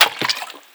SFX_Liquid_Splash.wav